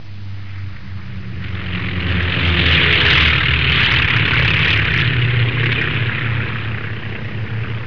دانلود آهنگ طیاره 28 از افکت صوتی حمل و نقل
دانلود صدای طیاره 28 از ساعد نیوز با لینک مستقیم و کیفیت بالا
جلوه های صوتی